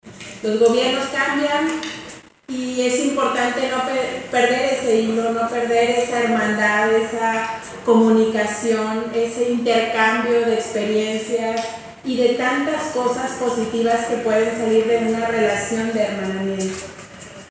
AudioBoletines
Lorena Alfaro, Presidenta de Irapuato
Alexander Zacarias, Secretario de Cultura y Diversidad de Green Bay, Winsconsin